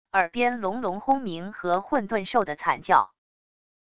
耳边隆隆轰鸣和混沌兽的惨叫wav音频